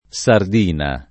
sarda [S#rda] s. f. («pesce») — propr. femm. dell’etn. sardo — più com. sardina [